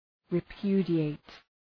Shkrimi fonetik {rı’pju:dı,eıt}